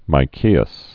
(mī-kēəs)